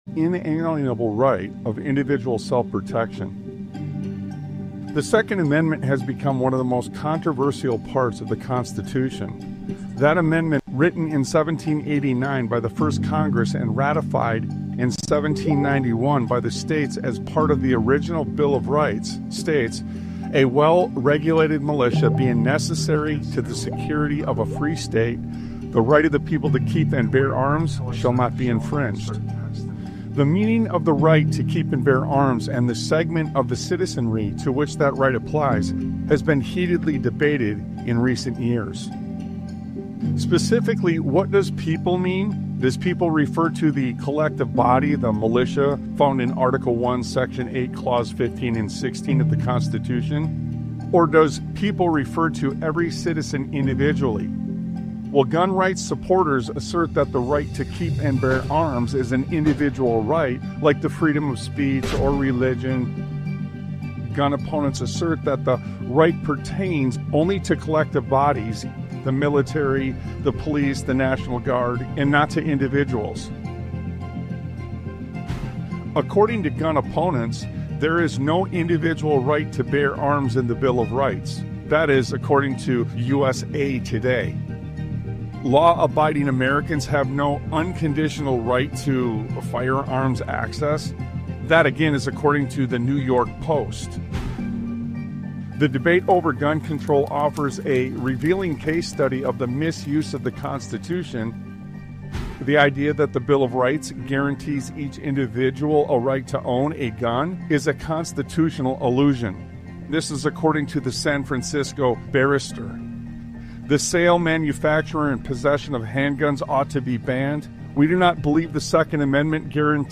Talk Show Episode, Audio Podcast, Sons of Liberty Radio and What Turning Into A Hell Begins To Look Like on , show guests , about What Turning Into A Hell Begins To Look Like, categorized as Education,History,Military,News,Politics & Government,Religion,Society and Culture,Theory & Conspiracy